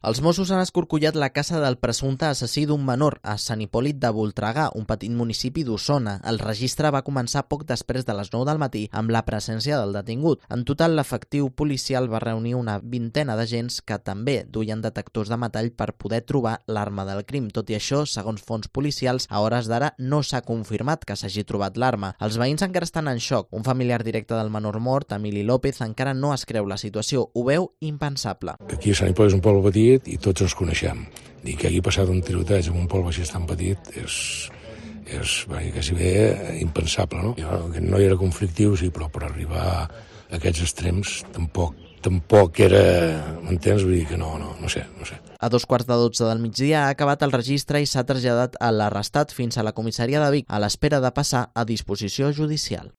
Crónica sobre el asesinato a un joven de 15 años en Sant Hipòlit de Voltregà